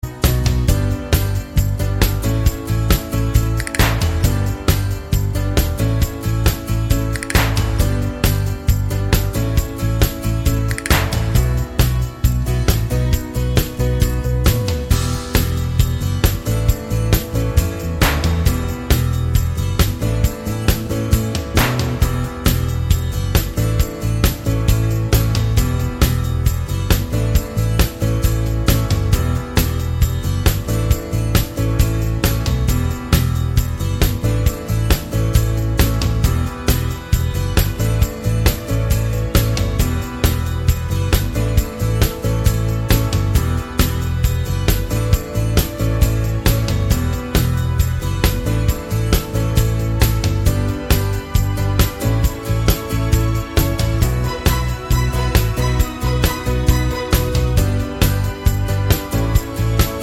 no Backing Vocals Rock 4:04 Buy £1.50